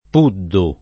[ p 2 ddu ]